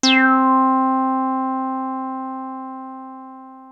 303 C4  9.wav